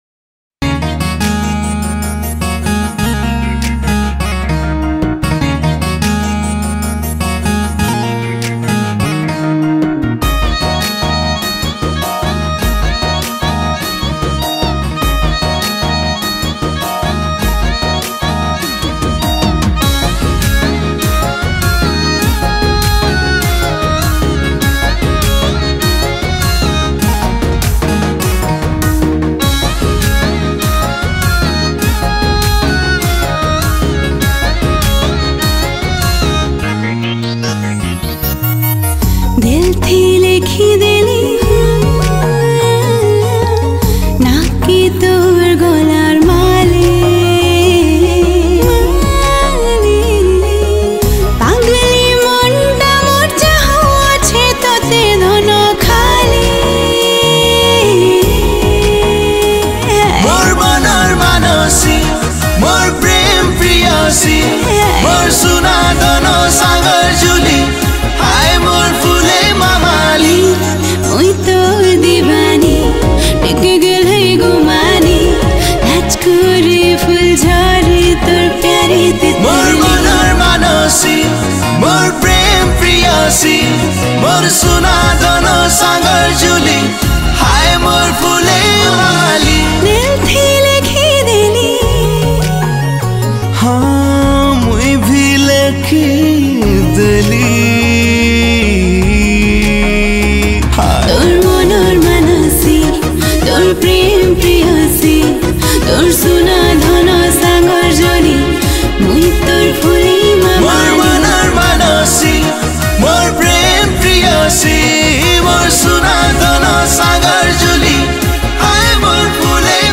Sambalpuri Song